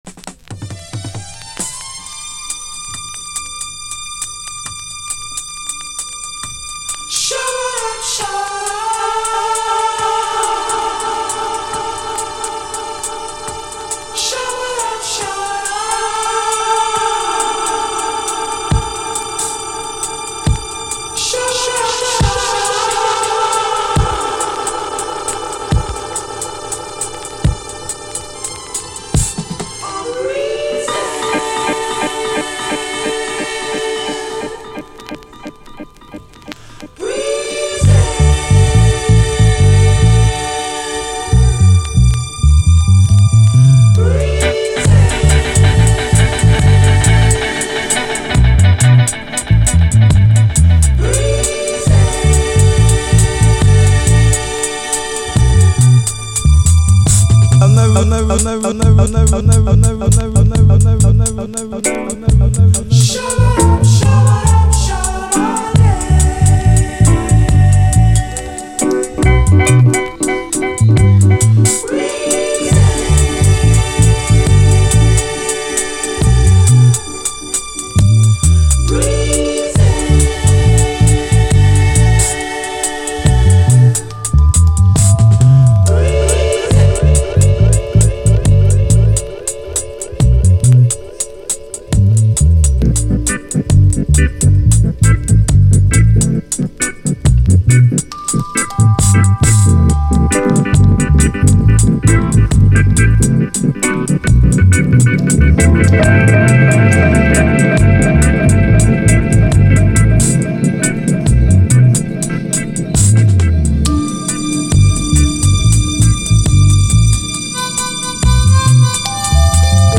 REGGAE, 7INCH
試聴ファイルはこの盤からの録音です
所々に風のSEが入る演出、美しいコーラスが伸びやかな最高メロウ・チューン！
ダブ処理された前述のコーラスがさらに美しく響く極上メロウ・ダブ！